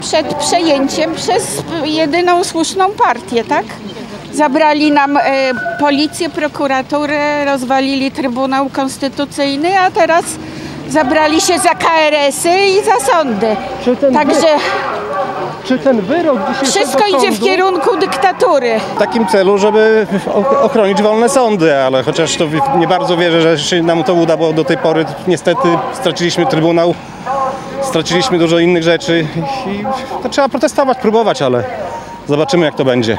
W rozmowie z Radiem 5 protestujący mówili, że ich zdaniem niezależność polskich sądów jest zagrożona i wymagają one obrony.